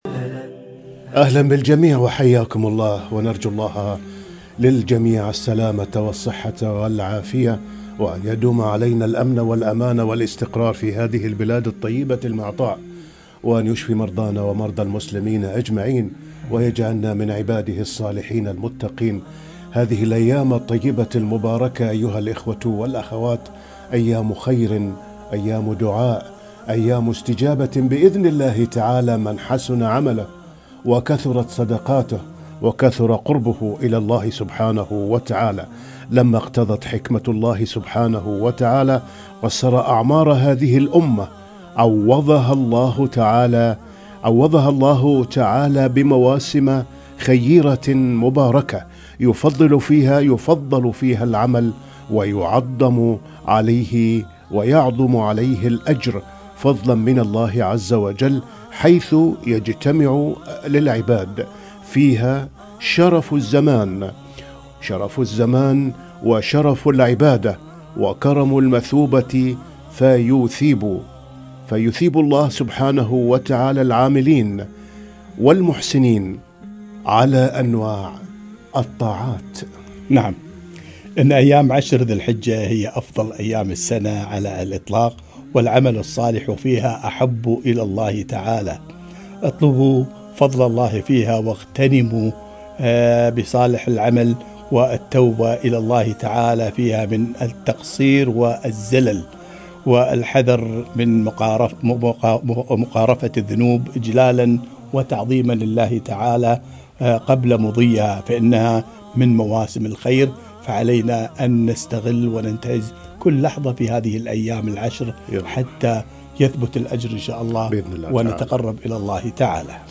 شهر ذي الحجة فضائل وأحكام - لقاء إذاعي عبر إذاعة القرآن الكريم